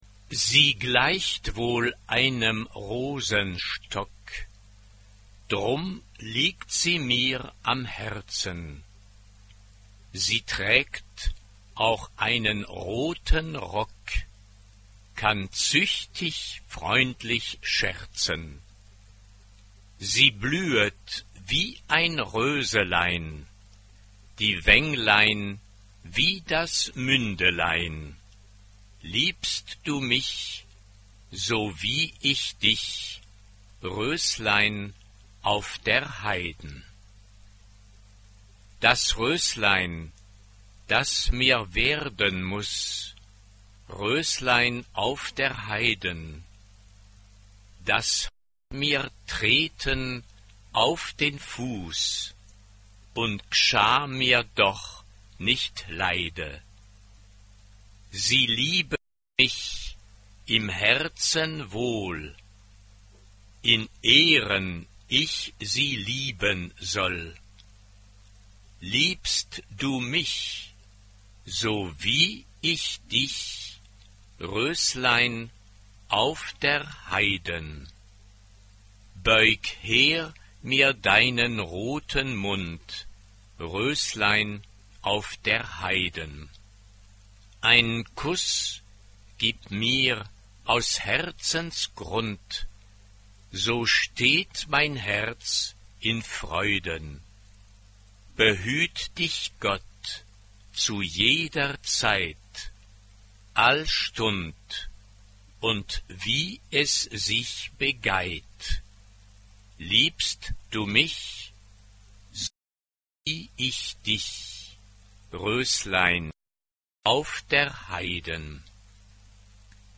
SSAA (4 Kinderchor ODER Frauenchor Stimmen).